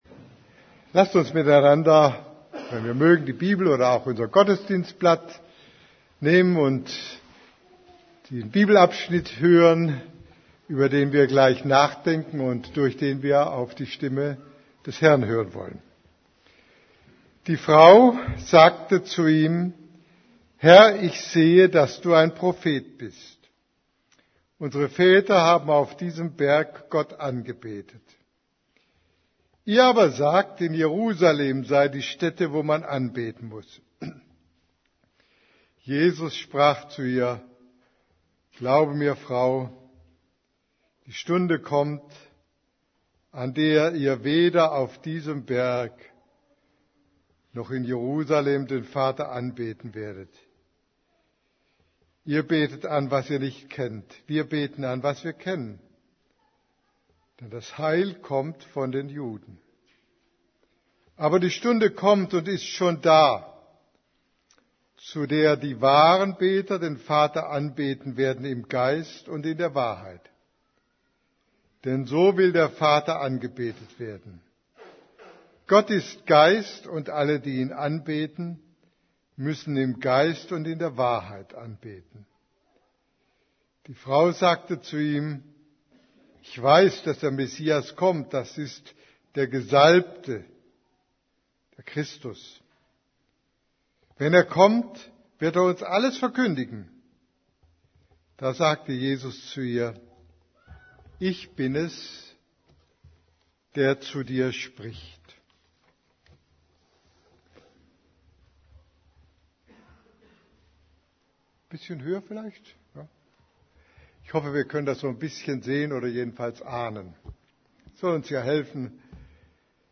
Predigt vom 15.